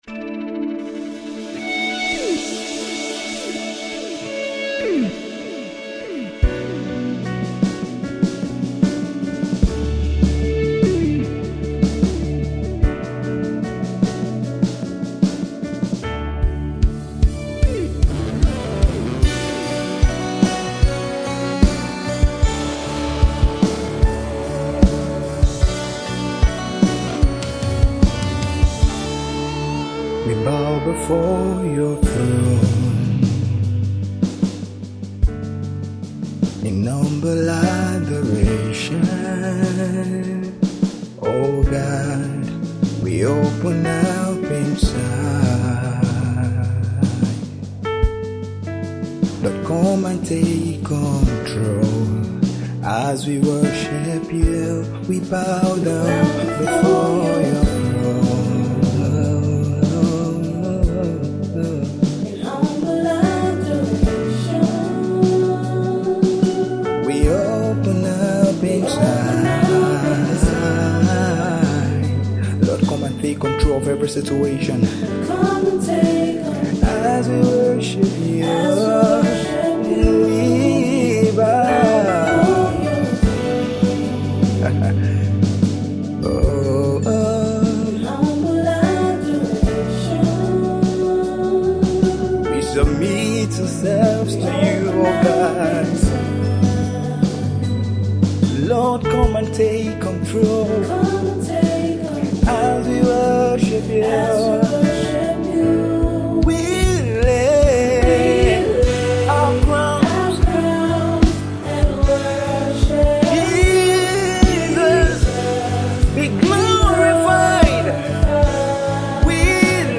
is a simple worship song